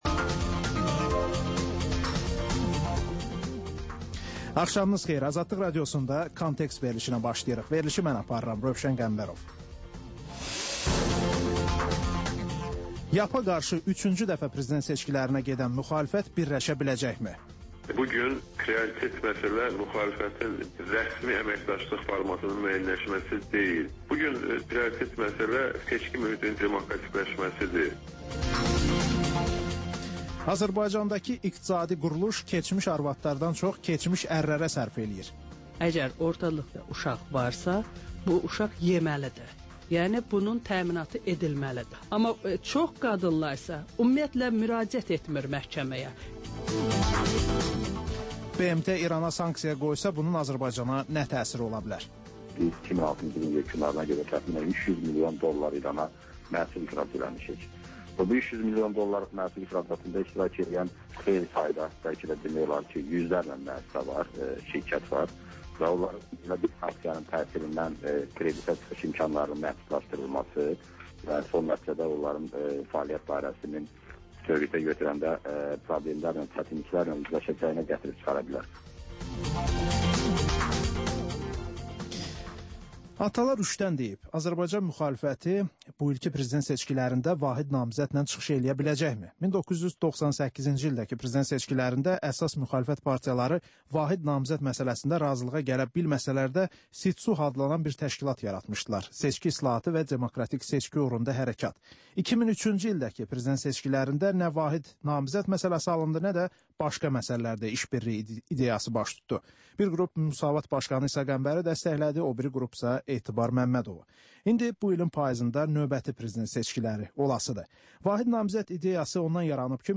Hadisələrin təhlili, müsahibələr və xüsusi verilişlər.